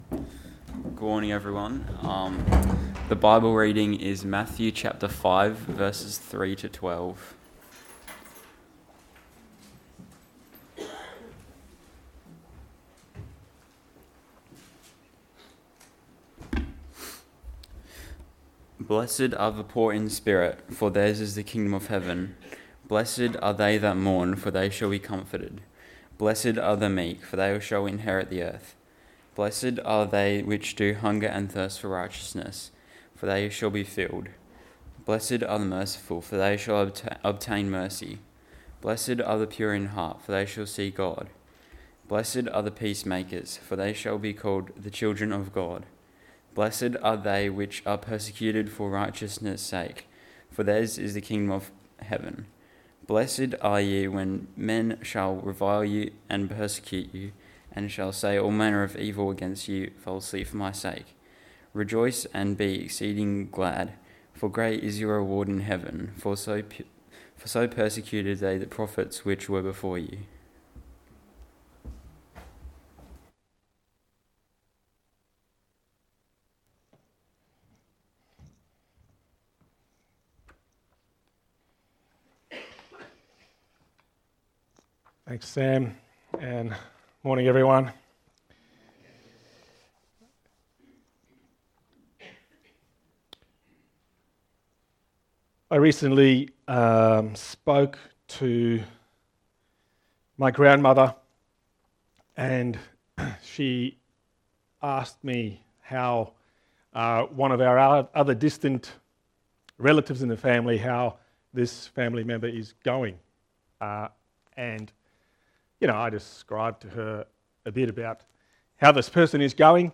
Matthew 5 3 12 Et Sermon on the Mount.mp3